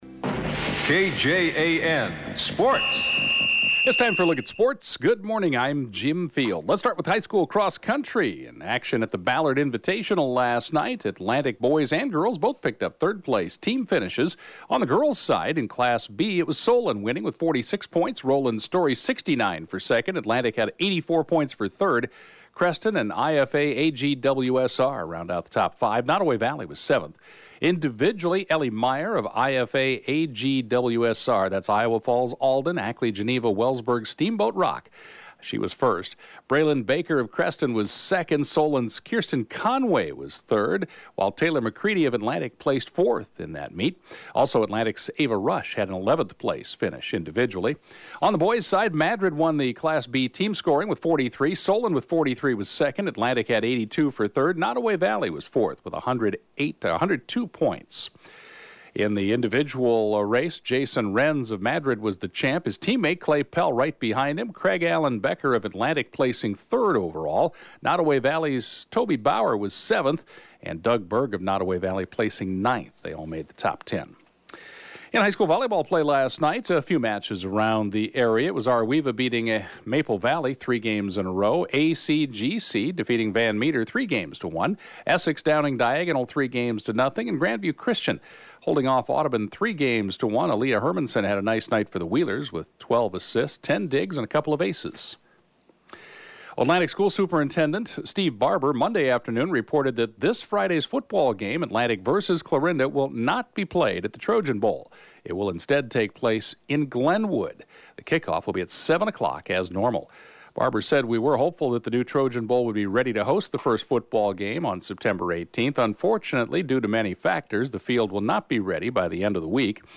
(Podcast) KJAN Morning Sports report, 9/15/20